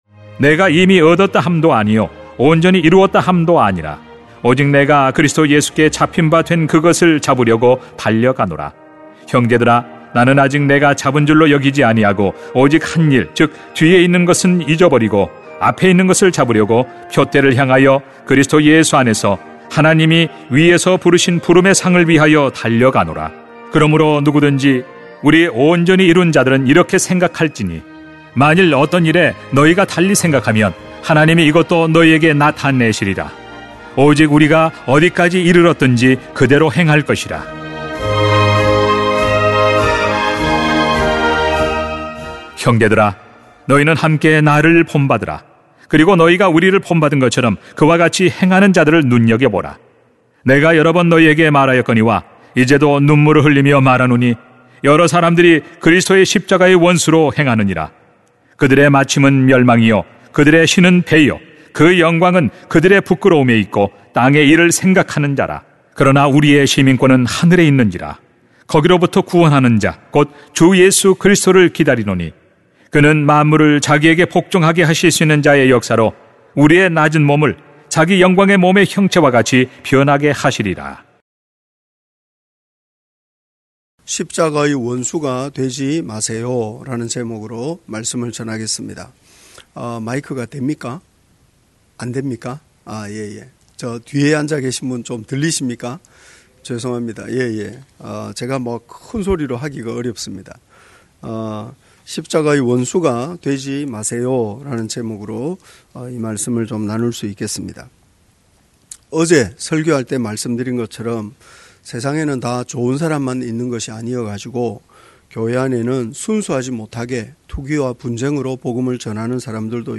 [빌 3:12-21] 십자가의 원수가 되지 마세요 > 새벽기도회 | 전주제자교회